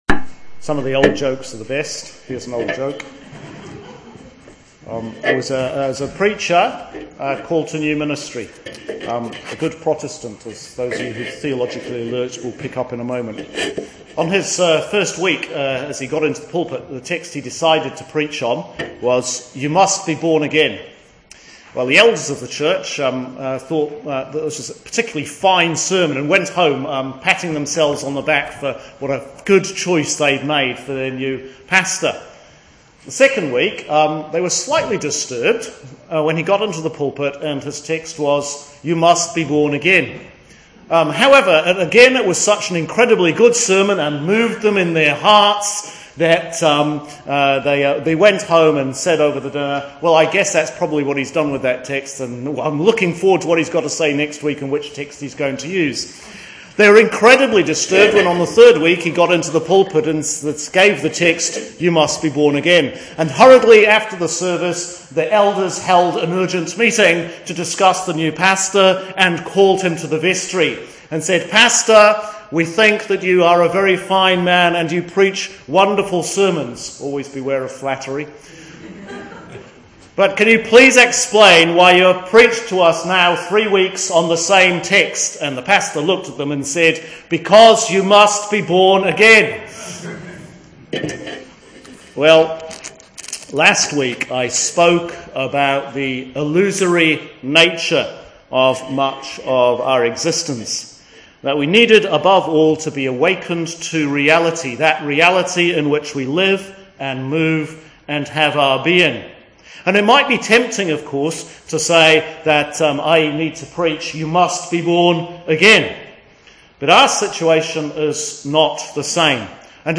Sermon for Lent 2 – Year A – 16th March 2014 ‘You must be born again’